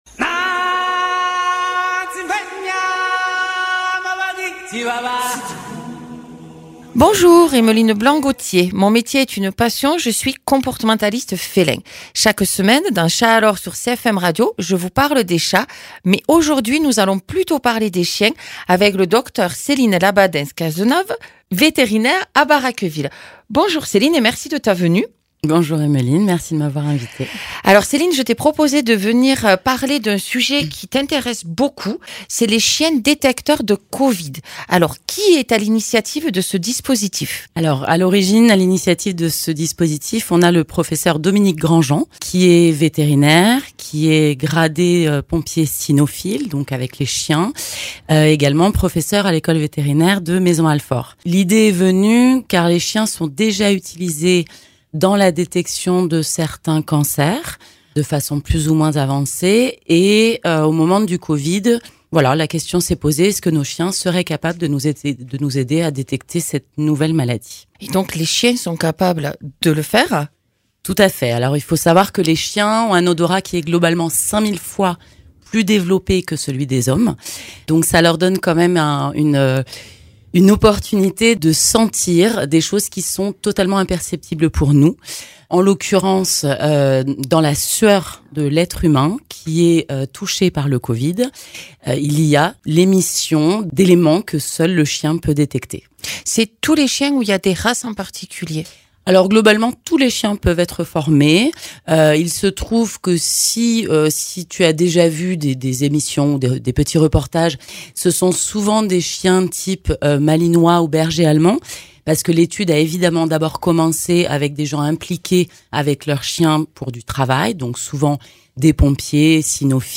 Docteur vétérinaire